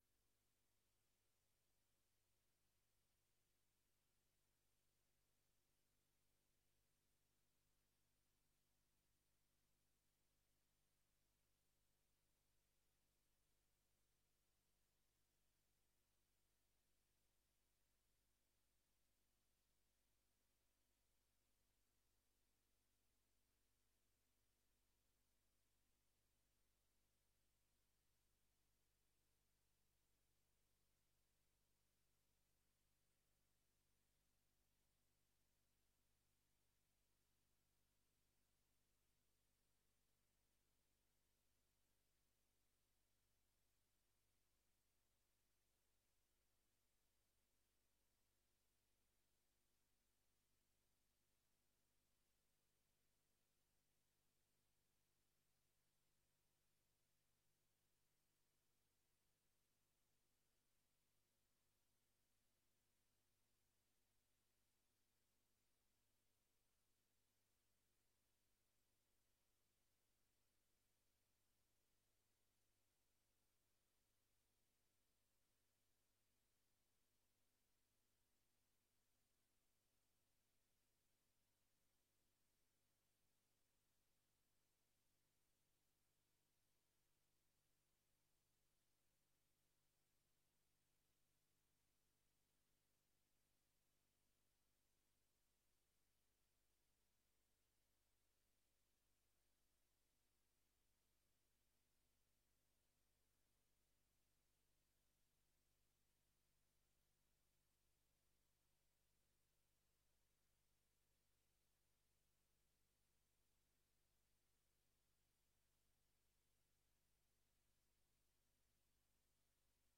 Raadscommissie 18 juni 2024 20:00:00, Gemeente Renkum
Locatie: Raadzaal